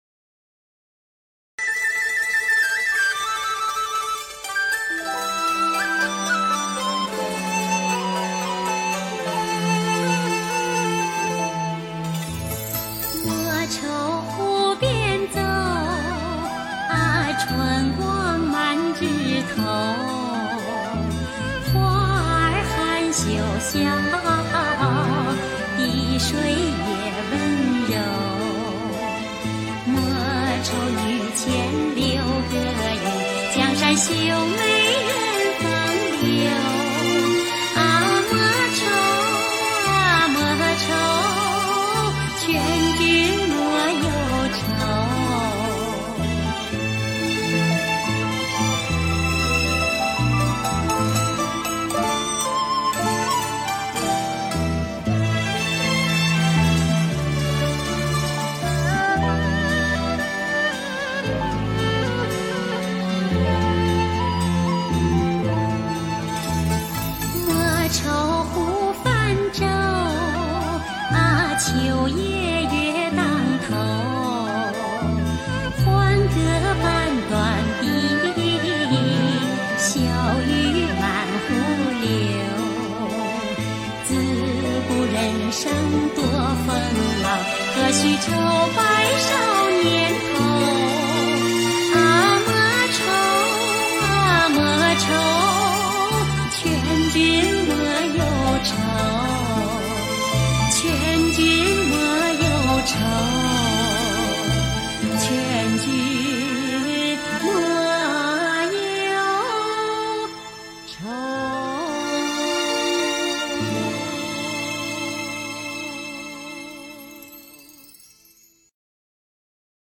她的演唱音色甜美、韵味纯正，特别
是她用吴侬软语把江南民歌唱得惟妙惟肖、出神入化，宛如江南水乡涟漪之波，
情感诚挚、深邃真切，是中国民族歌坛上不可多得的一朵艳丽奇葩。